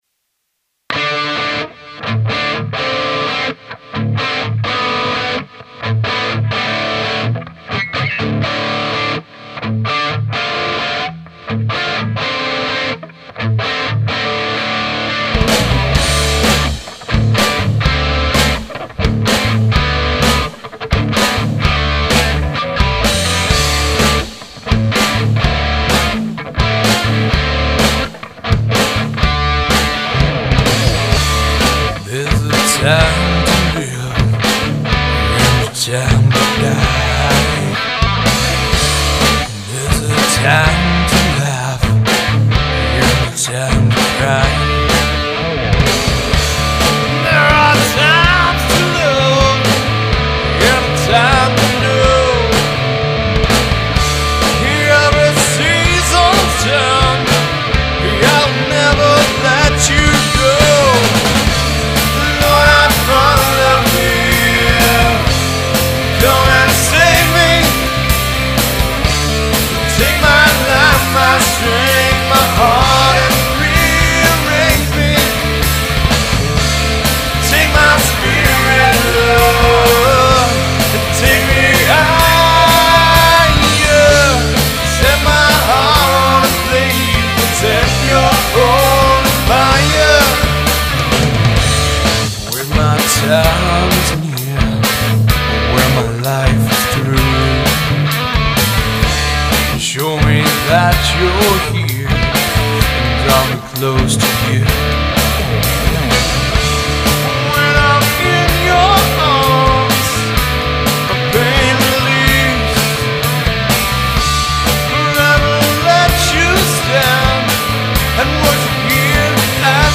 Christian Worship/Rock Band
Christian alternative rock band